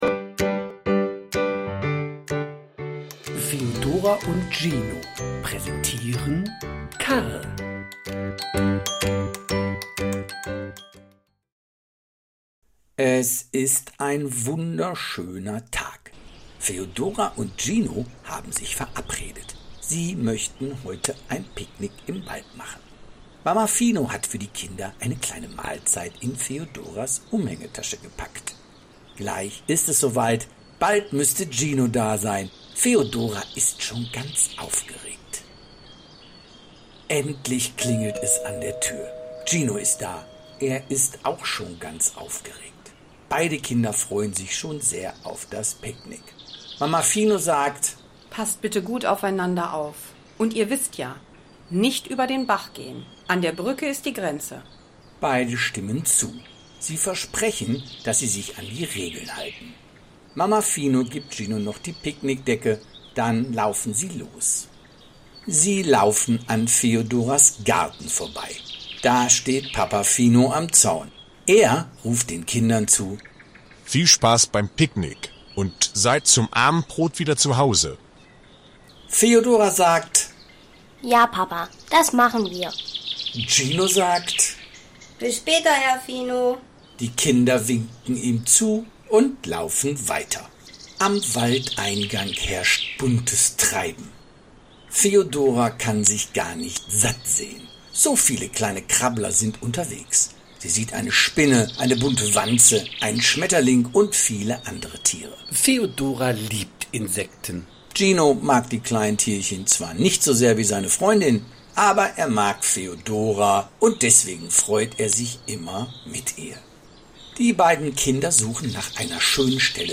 Karl – Kinderhörspiel Freundschaft Zugehörigkeit für Kindergartenkinder
A lovingly narrated version full of emotion, ideal for falling asleep, relaxing, or simply listening.